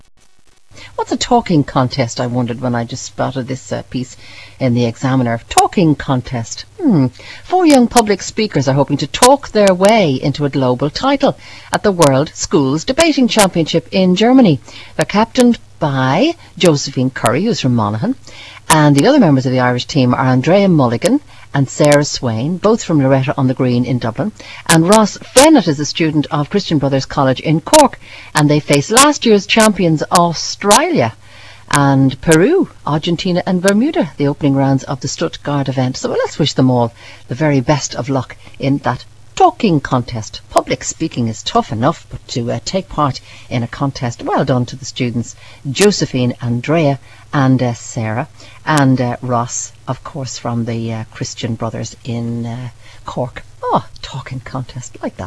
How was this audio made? The Team were wished luck on RTE Radio One.